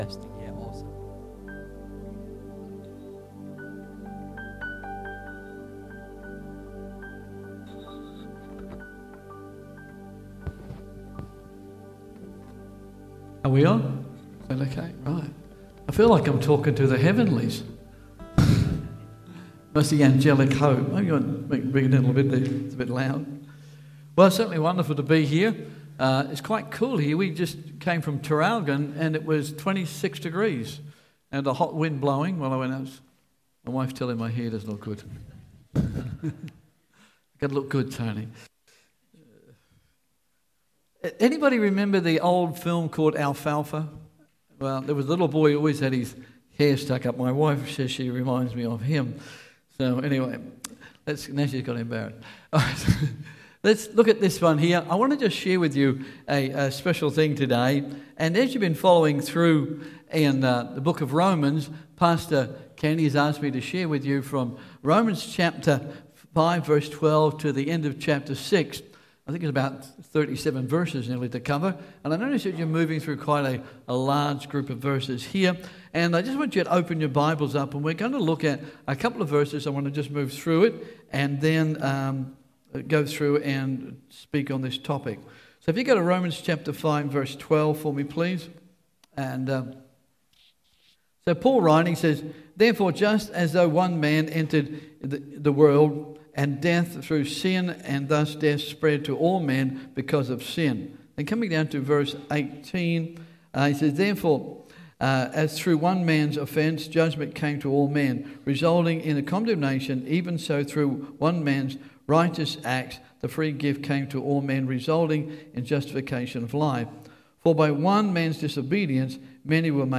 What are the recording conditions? English Worship Service - 1 October 2023